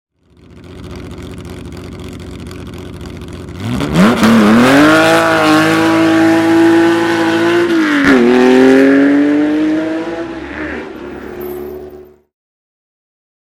Porsche Carrera 6 (906) von 1966 - Start am Arosa ClassicCar Bergrennen 2013